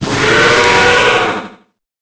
Cri d'Hydragon dans Pokémon Épée et Bouclier.